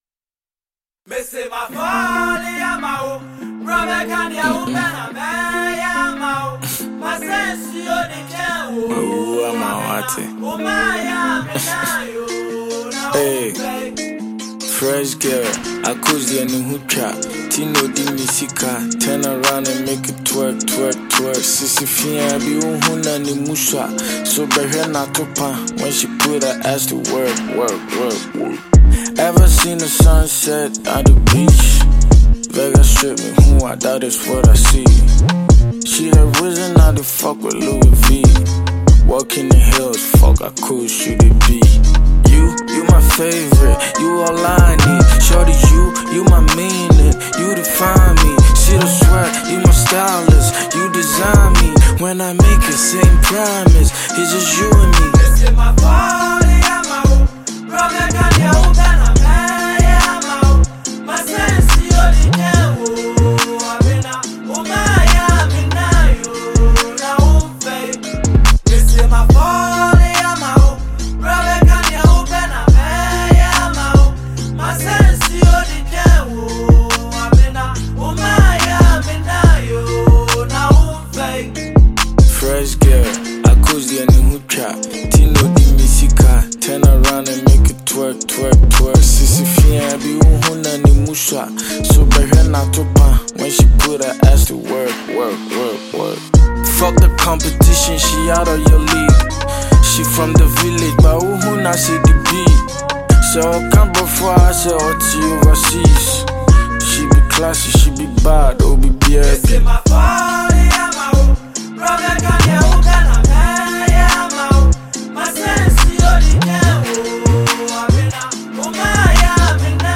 Ghanaian multiple award-winning rapper and singer